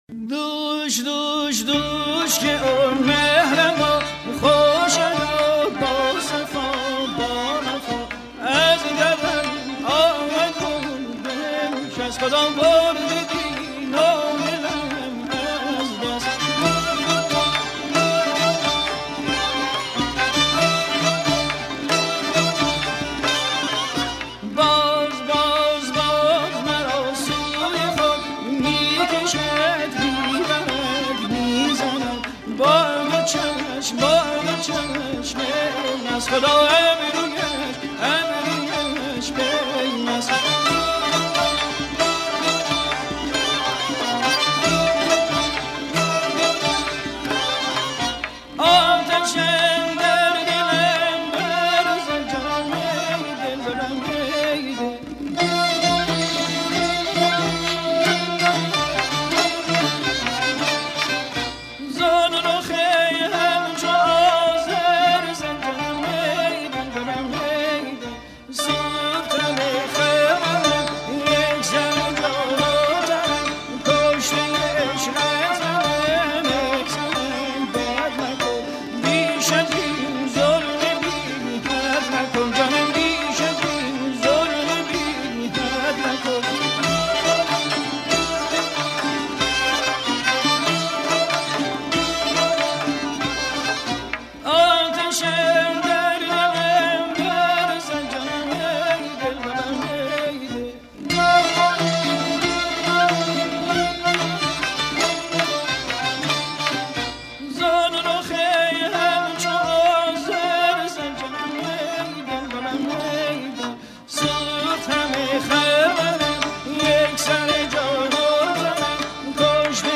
با صدای آسمانی